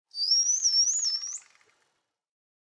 Minecraft Version Minecraft Version 25w18a Latest Release | Latest Snapshot 25w18a / assets / minecraft / sounds / mob / dolphin / idle_water10.ogg Compare With Compare With Latest Release | Latest Snapshot
idle_water10.ogg